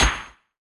hitSmallPlayer.wav